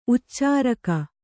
♪ uccāraka